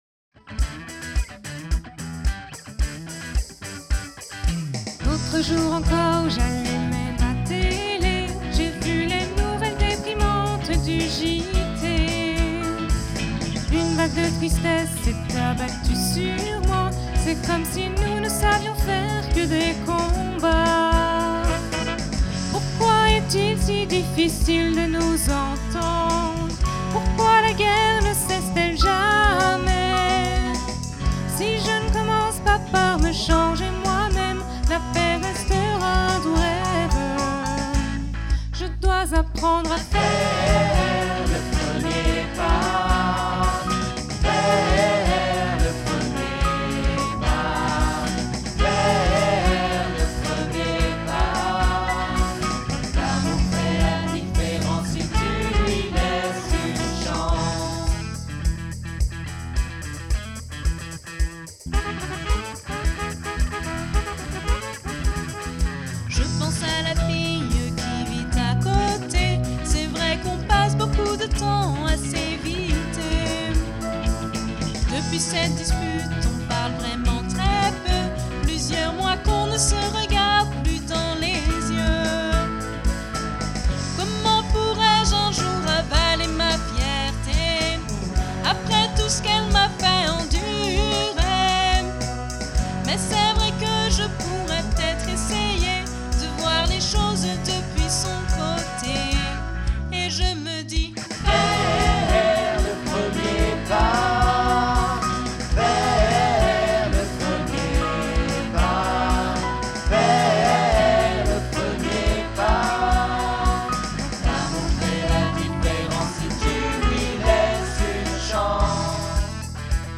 Enregistré lors du week-end unison : ./Le premier pas (bend don't break).mp3